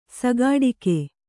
♪ sagāḍike